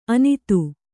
♪ anitu